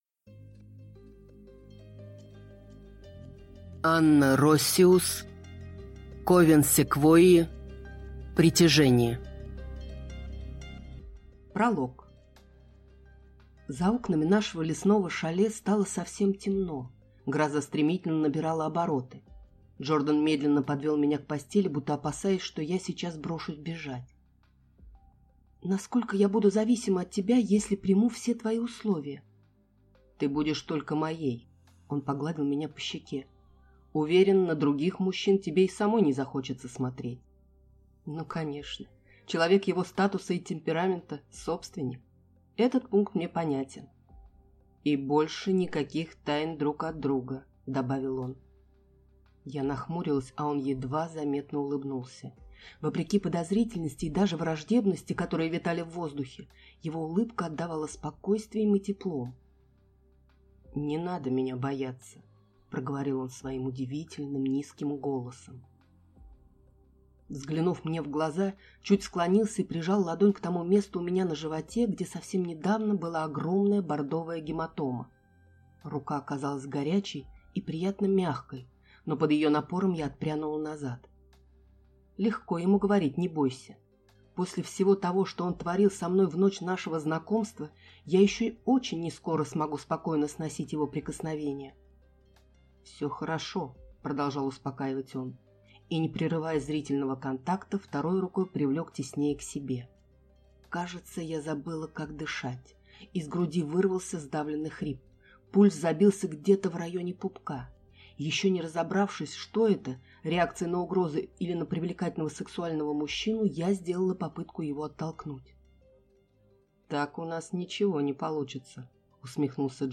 Аудиокнига Никто кроме тебя | Библиотека аудиокниг